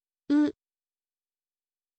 ออกเสียง: u, อึ, อุ
เสียงสระนี้ใกล้เคียงกับ “อึ” ค่ะ แต่ในบางกรณีจะออกเสียงว่า “อุ” ค่ะ